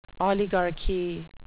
oligarchy (OLI-gar-kee) noun
Pronunciation: